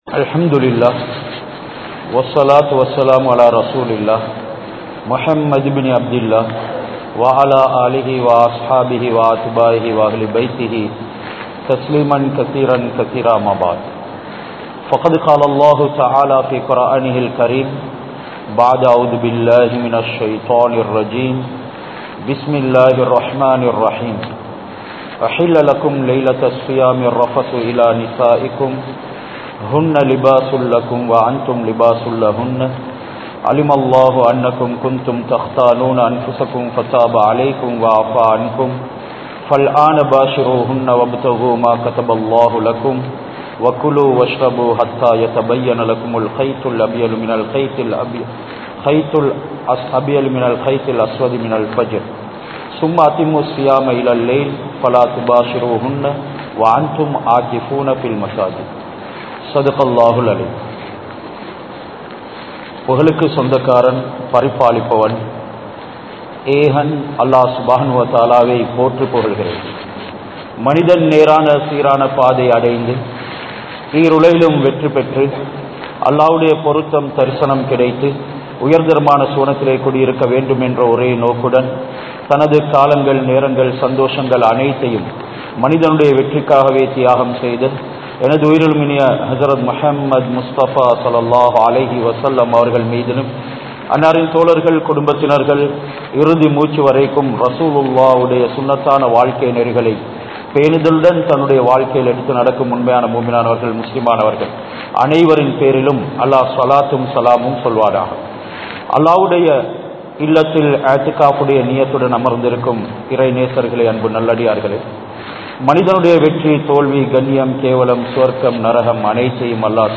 Haraamaana Paarvai Ullaththitku Inpama? Thunpama? (ஹராமான பார்வை உள்ளத்திற்கு இன்பமா? துன்பமா?) | Audio Bayans | All Ceylon Muslim Youth Community | Addalaichenai
Vavuniya, Pattanichoor Mohiyadeen Jumua Masjith